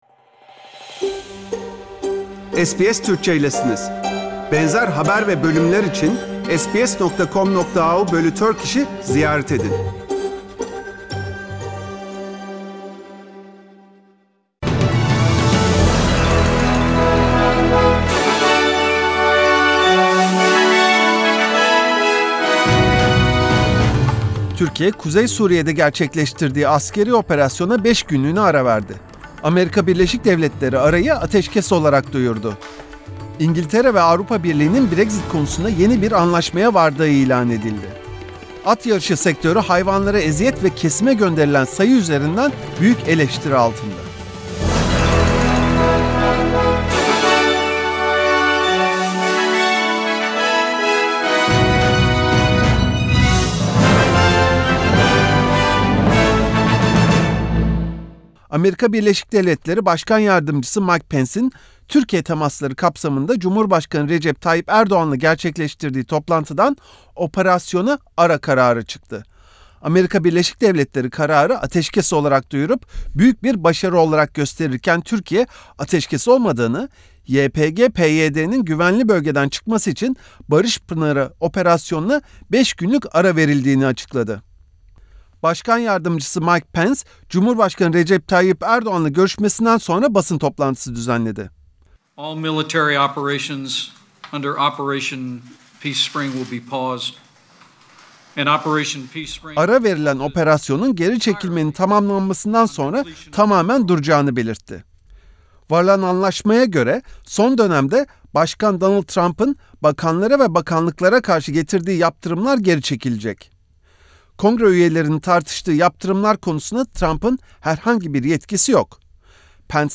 SBS Türkçe Programı'ndan Avustralya, Türkiye ve dünyadan haberler. BAŞLIKLAR ** Türkiye, kuzey Suriye’de yaptığı askeri operasyona 5 günlüğüne ara verdi.